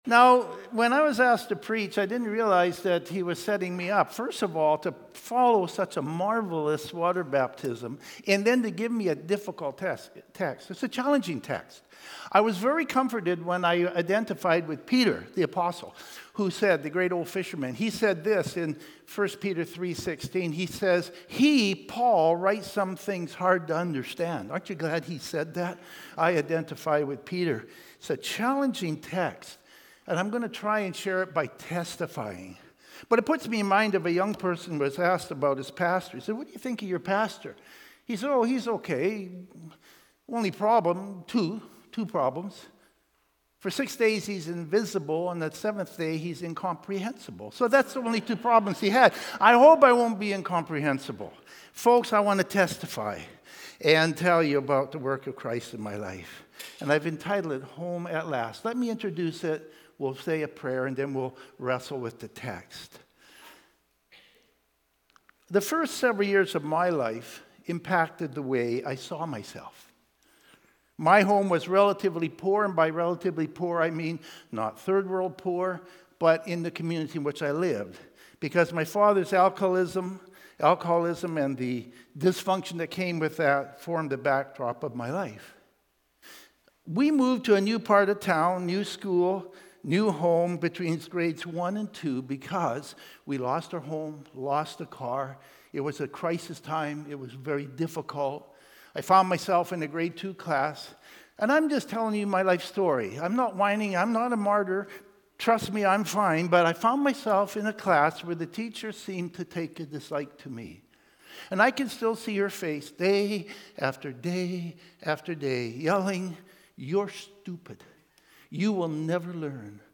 Aldergrove Sermons | North Langley Community Church